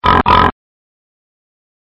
Pig Oinking
Pig oinking 2x sound effect. Sound of a pig on a farm oinking 2x in a row.